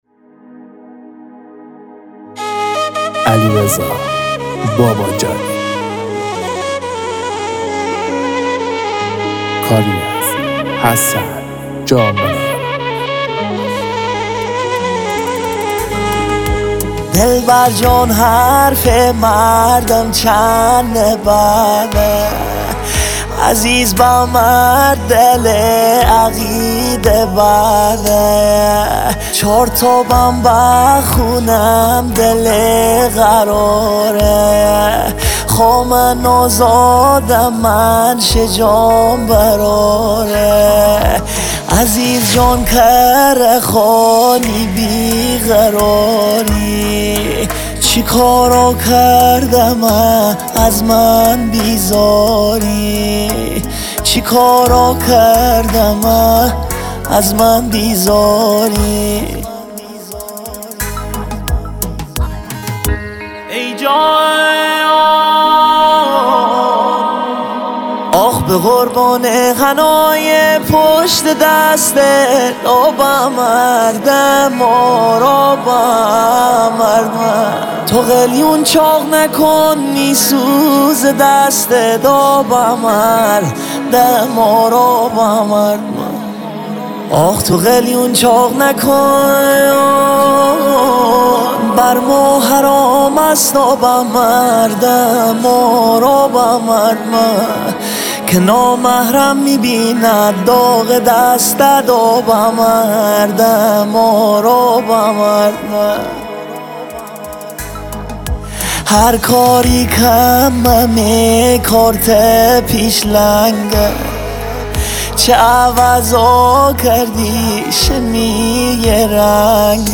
غمگین
آهنگ غمگین مازندرانی